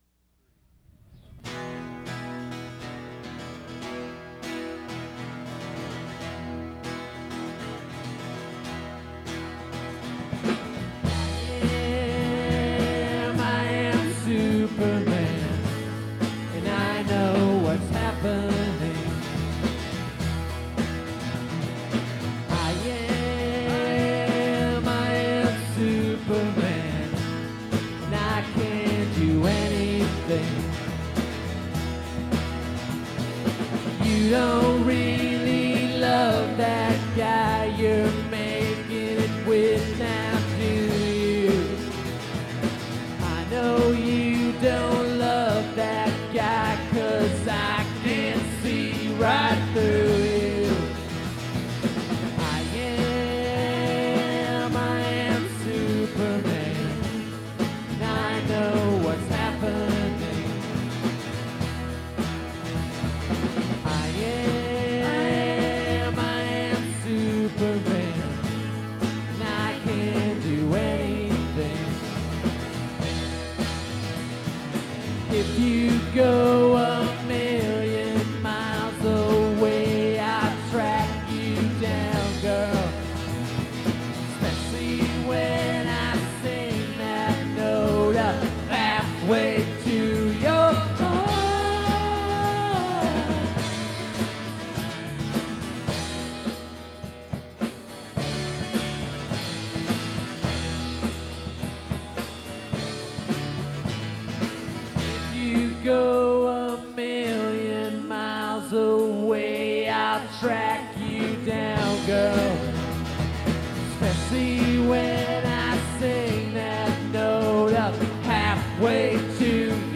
drums and vocals